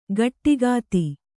♪ gaṭṭigāti